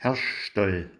hochdeutsch Gehlbergersch
Am Hirschstall  Herschstall